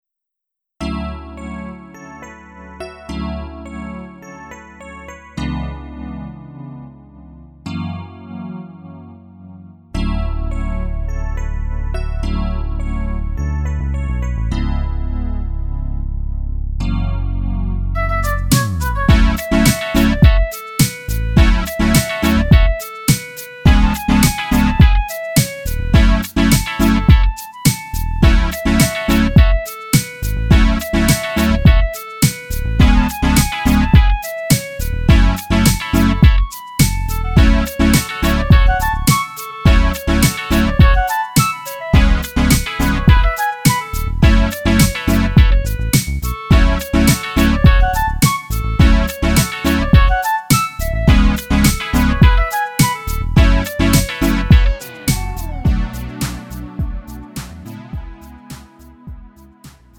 음정 원키 3:19
장르 가요 구분